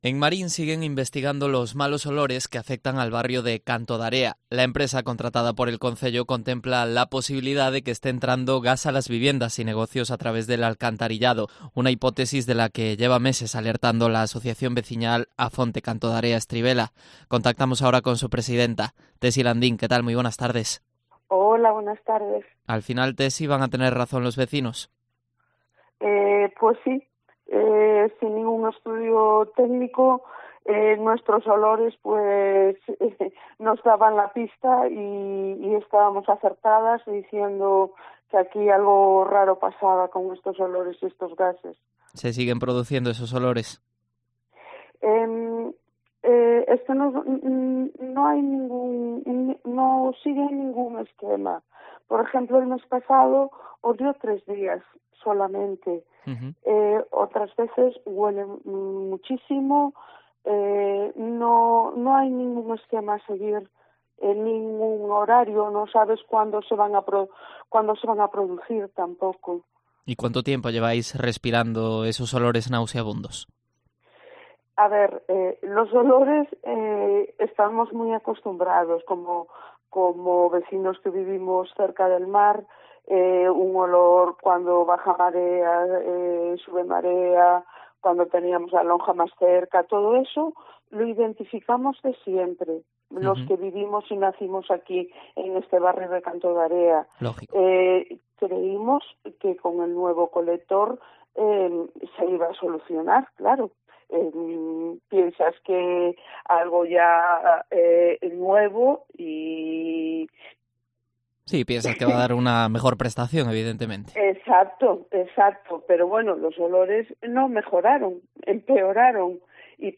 Entrevista
En Mediodía Cope han participado varias de las afectadas que, con cierta frecuencia, huelen " a huevo podrido " por las cañerías.